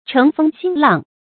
乘风兴浪 chéng fēng xīng làng 成语解释 谓趁机挑起事端。